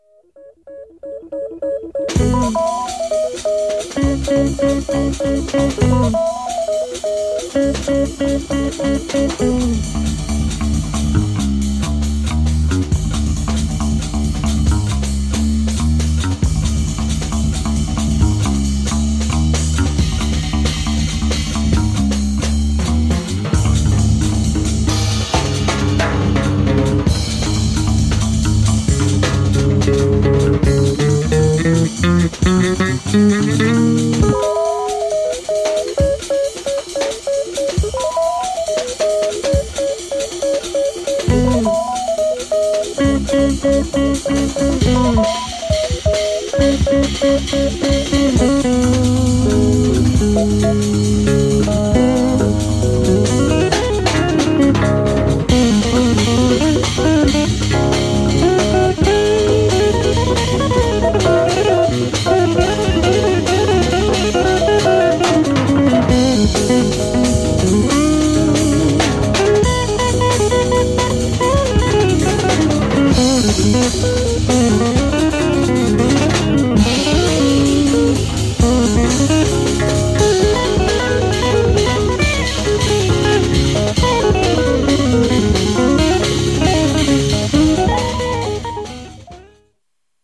Free-Jazz
bass
drums
guitar